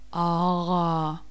Pulmonische Konsonantensymbole
Es ertönt der Konsonant in [a_a]-Umgebung.